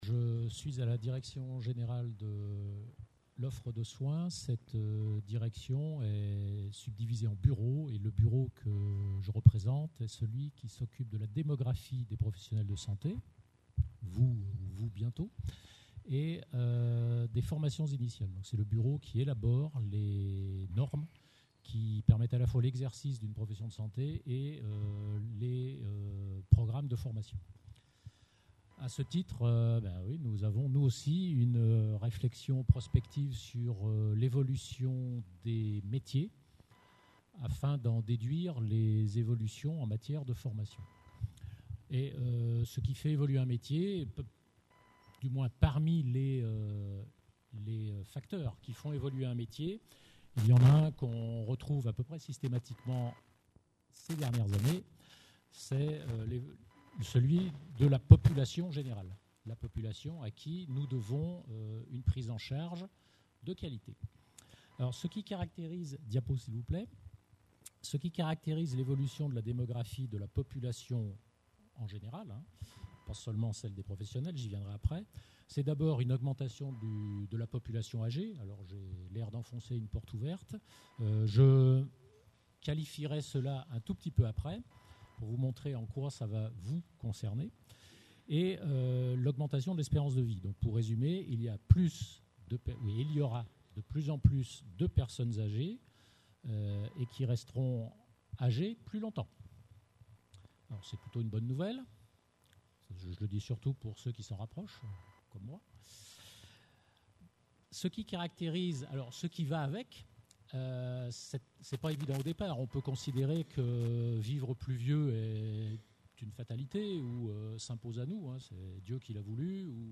Les problématiques de la dépendance de ces personnes âgées ont un impact sur l’activité des professionnels de santé, qui sont confrontés aux besoins de santé de la population : chronicisation, complexification des pathologies et fragilisation (dépendance médico-sociale). Conférence enregistrée lors 10ème Congrès National des Etudiants en Soins Infirmi